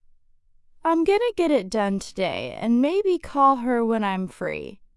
1) それでは最初のセンテンスを0.7倍速で流します。